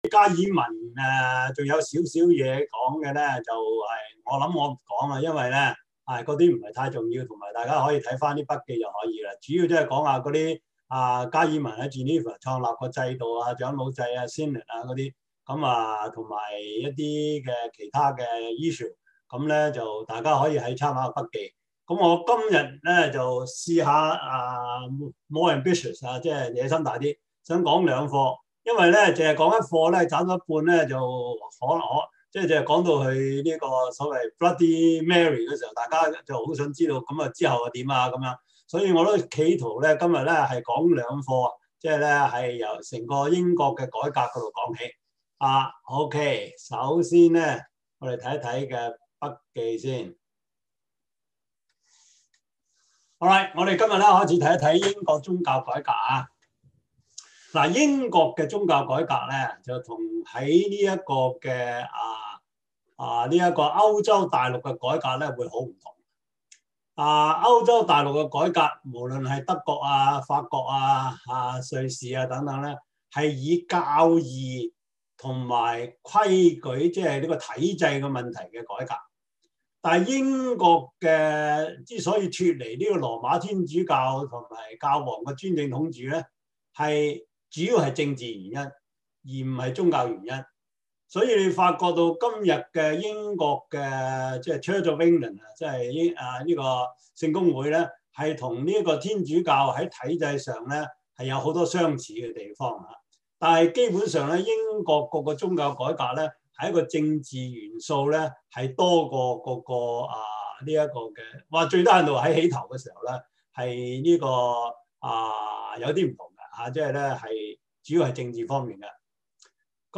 Series: 中文主日學, 教會歷史